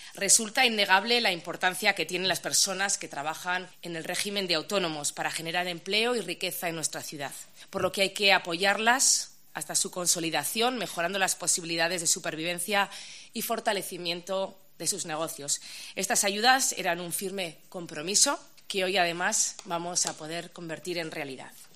Maider Etxebarria, alcaldesa de VItoria